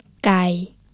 kai low tone.